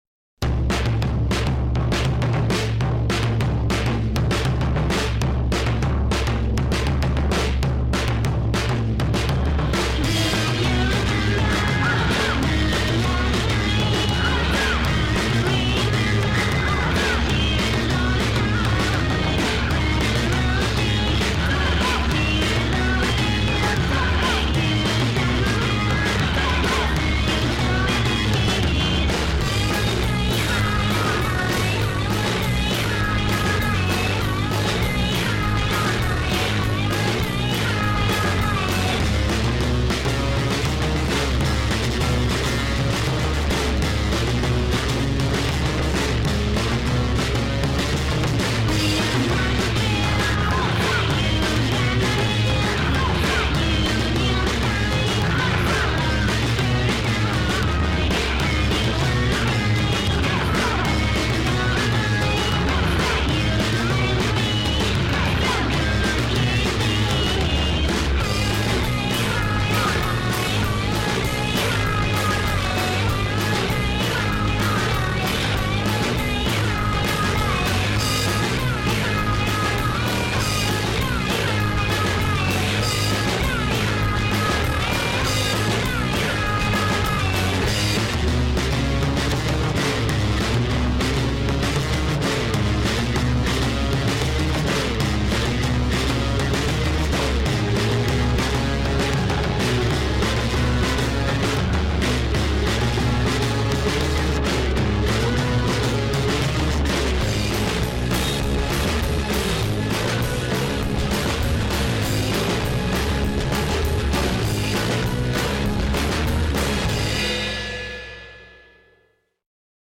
il duo basso batteria